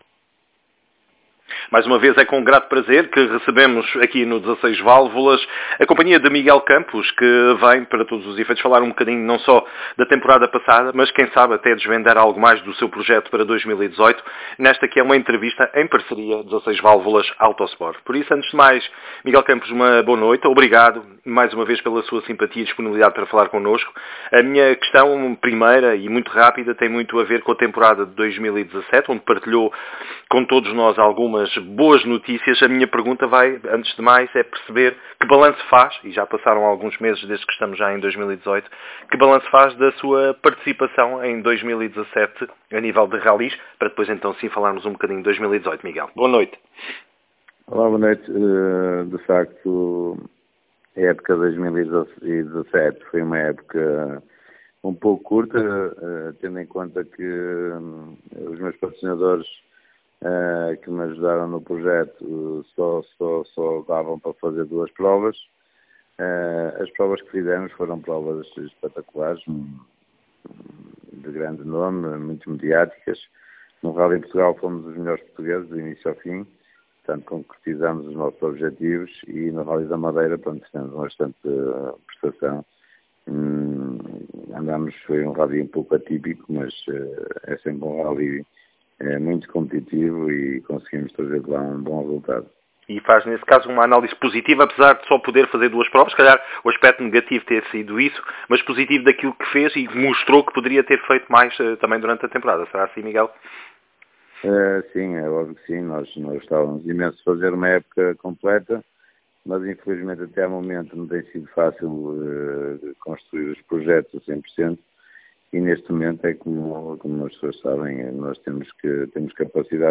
Em entrevista ao 16 Válvulas/AutoSport